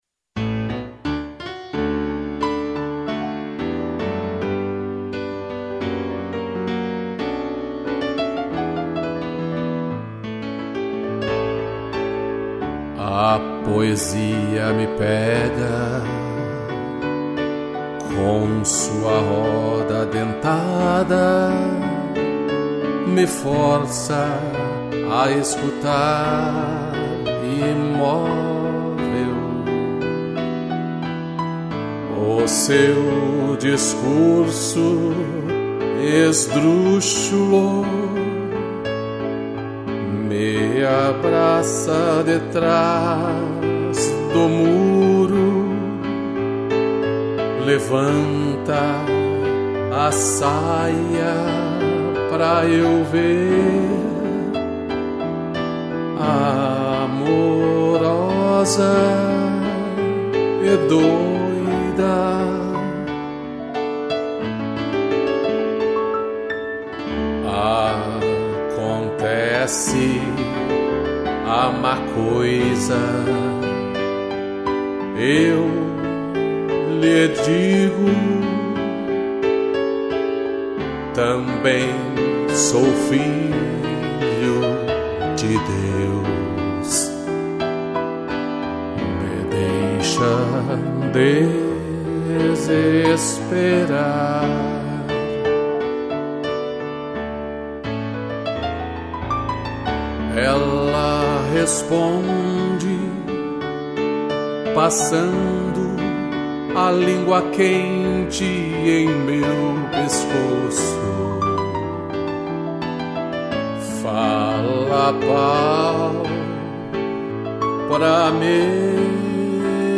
voz
2 pianos